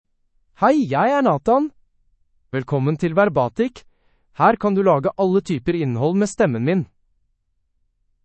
Nathan — Male Norwegian Bokmål AI voice
Voice sample
Listen to Nathan's male Norwegian Bokmål voice.
Male
Nathan delivers clear pronunciation with authentic Norway Norwegian Bokmål intonation, making your content sound professionally produced.